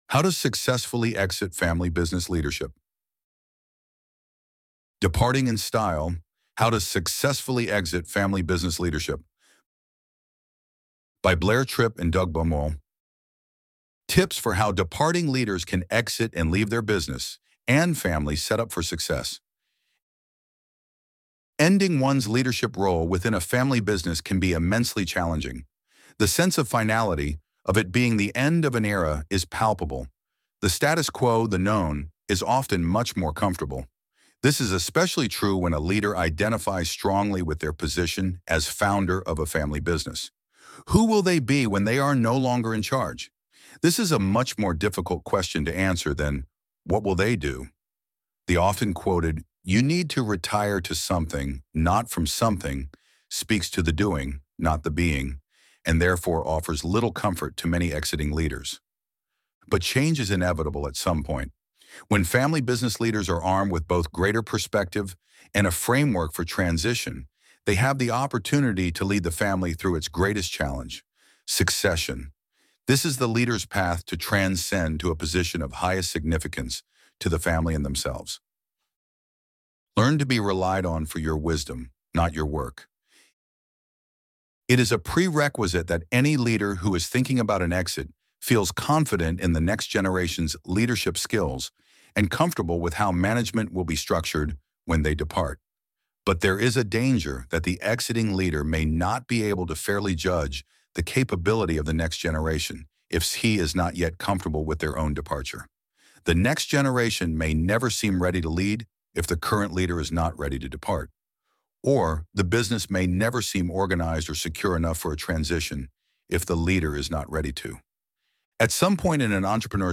Departing in Style: How to Successfully Exit Family Business Leadership Tips for how departing leaders can exit and leave their business—and family—setup for success Loading the Elevenlabs Text to Speech AudioNative Player...